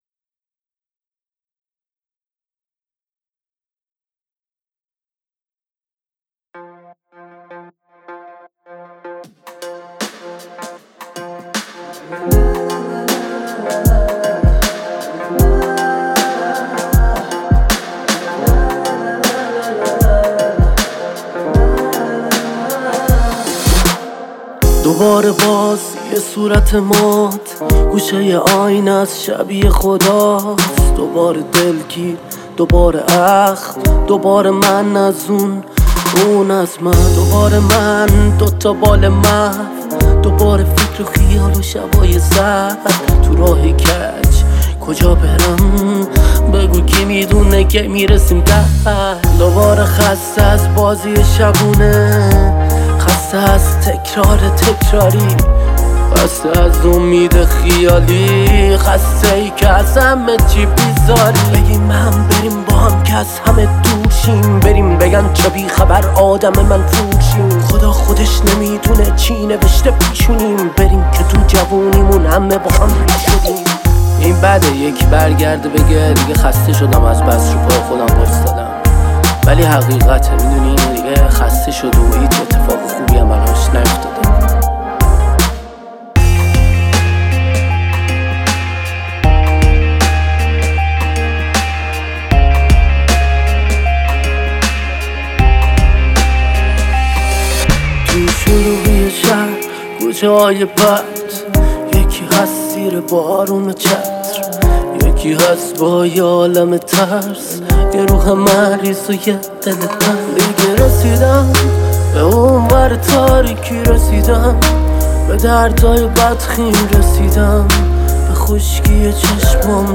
این آهنگ در سبک ژانر اهنگ پاپ خوانده شده است.